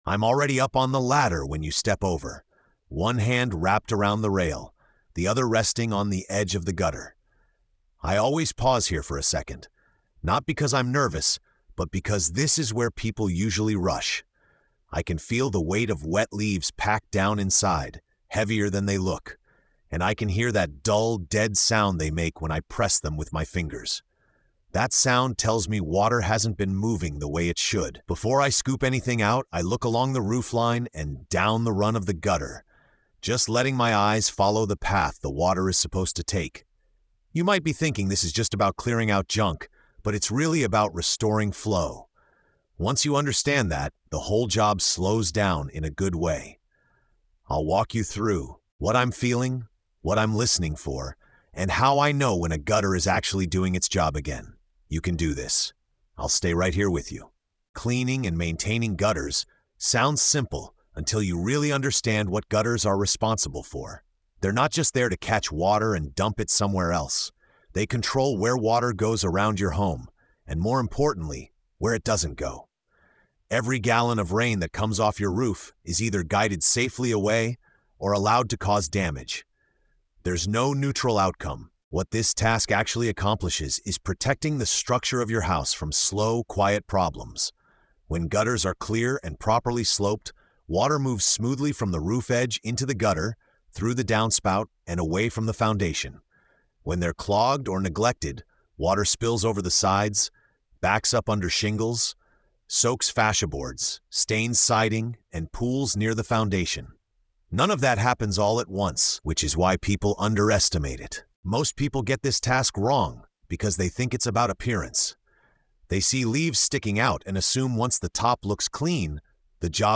Told entirely in first person, the episode transforms a routine maintenance task into a lesson about water control, patience, and professional judgment. As rainwater pathways are reopened, the listener learns how small oversights quietly lead to structural damage, and how awareness, feel, and observation separate rushed labor from skilled work. The emotional tone is calm, grounded, and reassuring, reinforcing that competence is built through understanding, not speed.